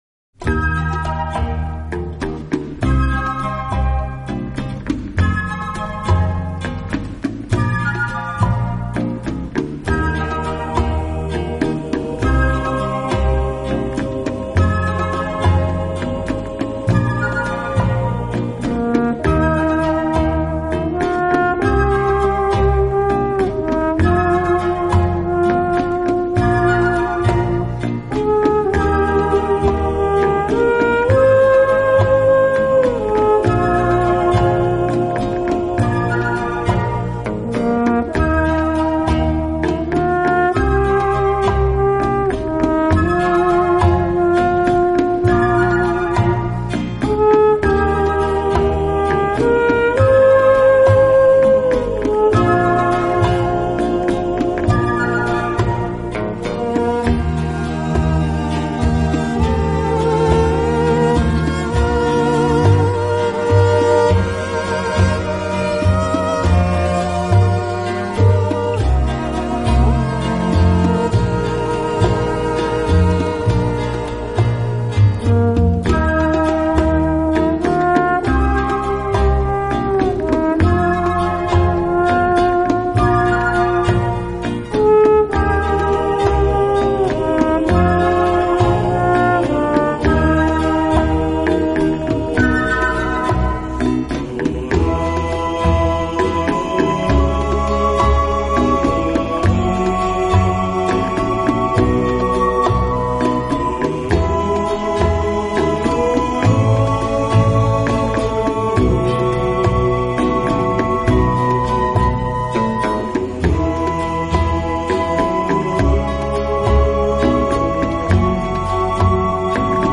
【轻音乐专辑】
小号的音色，让他演奏主旋律，而由弦乐器予以衬托铺垫，音乐风格迷人柔情，声情并
温情、柔软、浪漫是他的特色，也是他与德国众艺术家不同的地方。